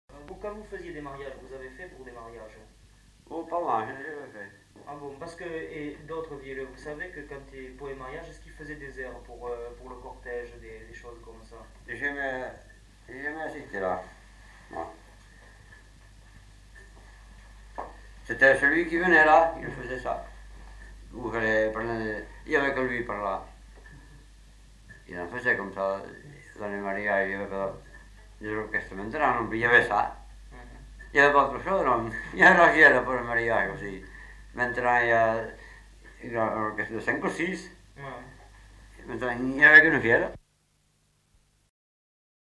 Lieu : Herré
Genre : témoignage thématique